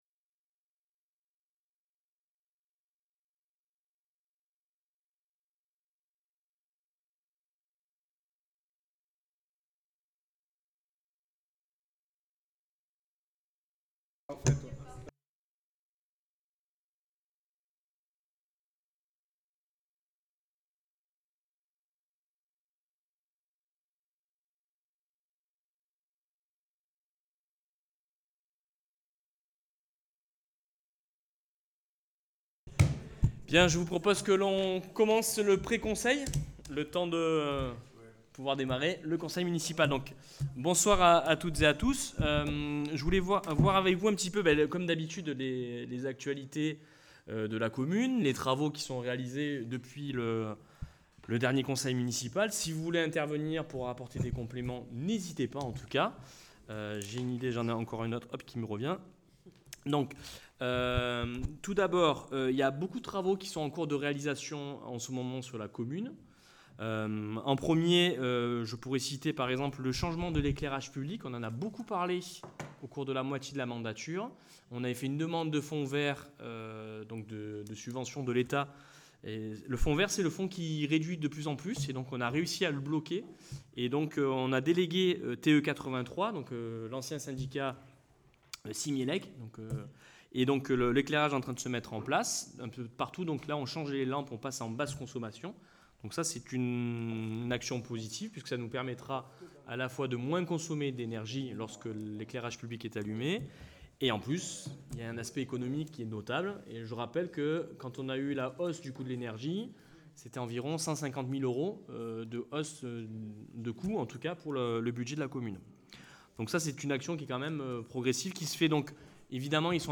Les Conseils Municipaux